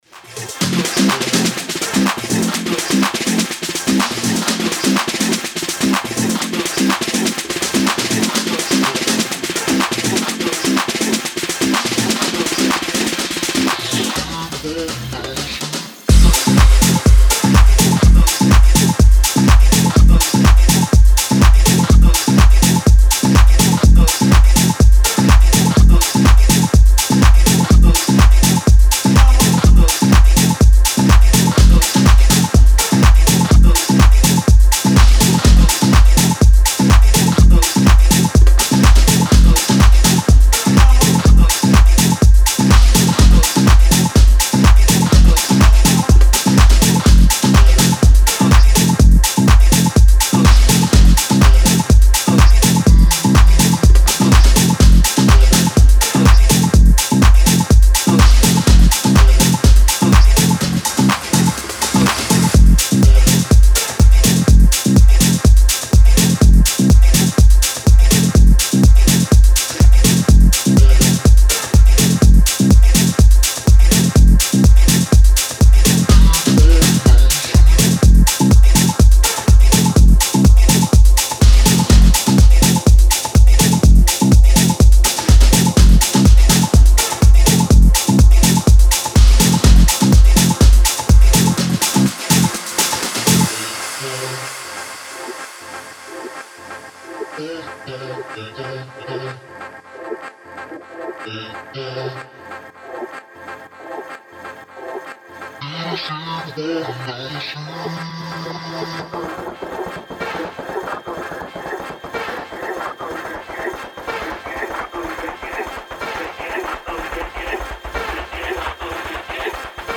Style: Tech House / House